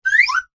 comedy_slide_whistle_up_002